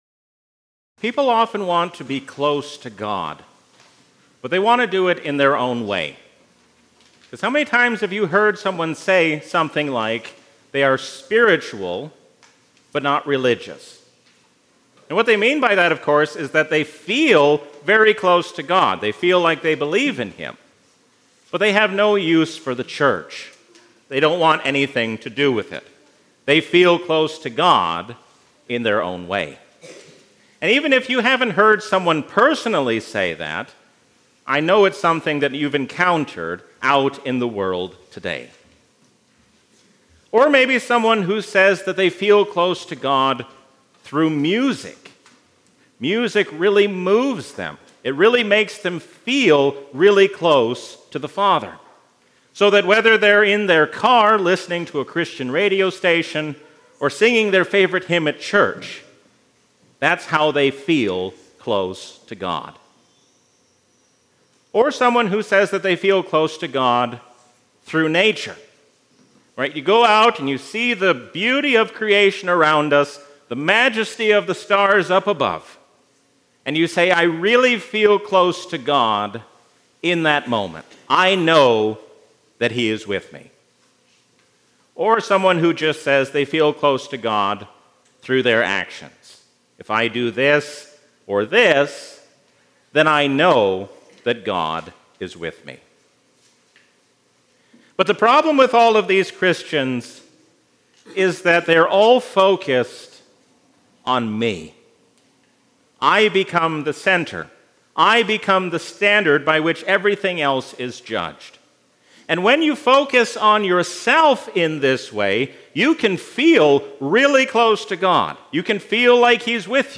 A sermon from the season "Trinity 2022." We do not draw near to God through what we do or what we feel, but through faith in Jesus Christ our Lord.